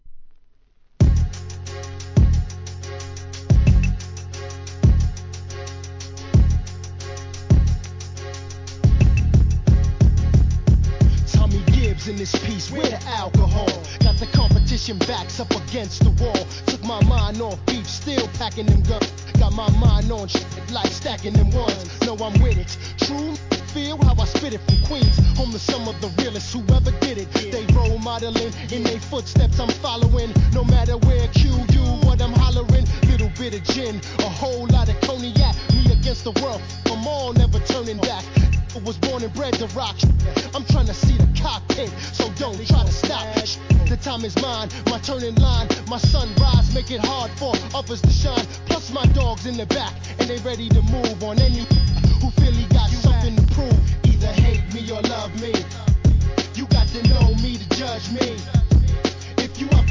HIP HOP/R&B
奥行きを感じるDOPEトラック・アンダーグランド!